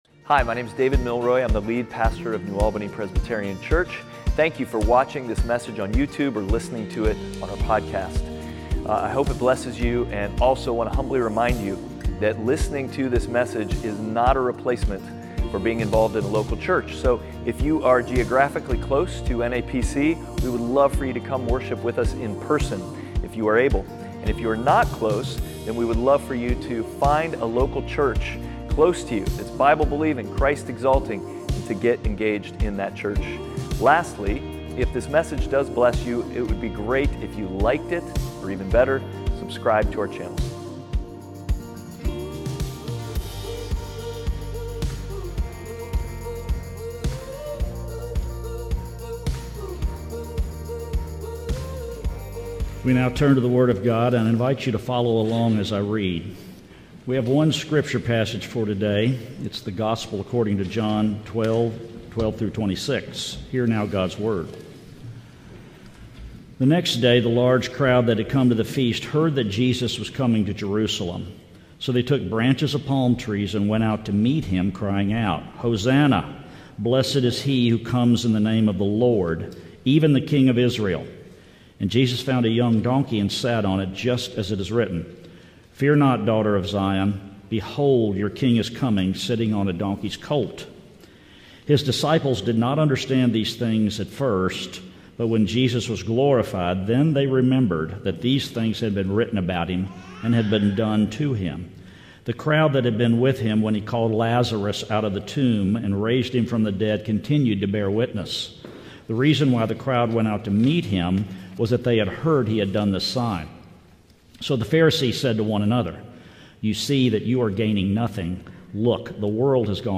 NAPC_Sermon3.29.26.mp3